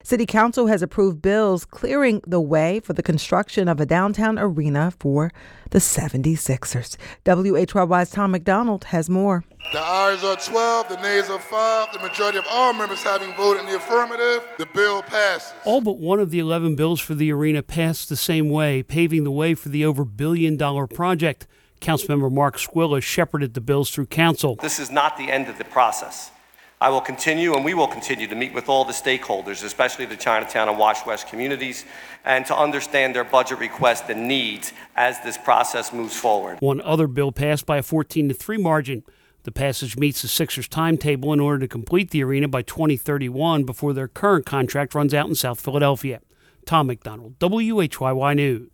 A survivor of one of the most brutal atrocities of the civil rights era shared her harrowing tale with students from Paul Robeson High School.